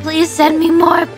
Worms speechbanks
Drop.wav